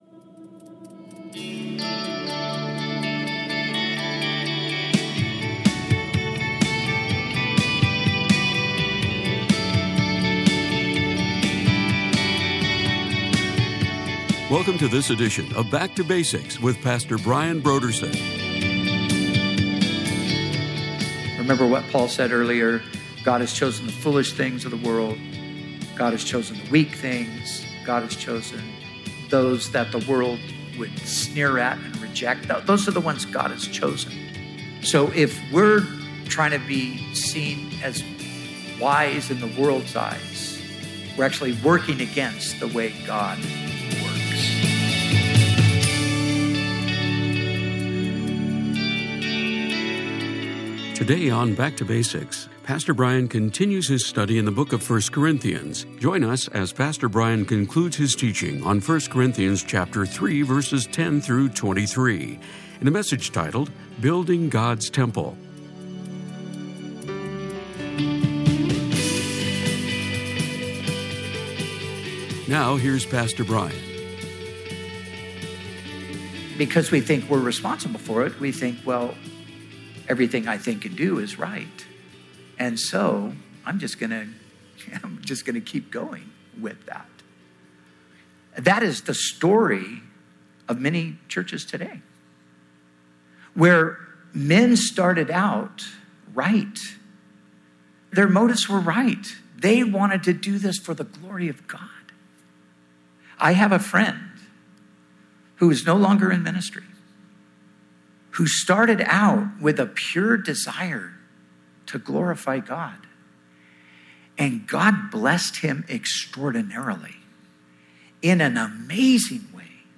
Bible teaching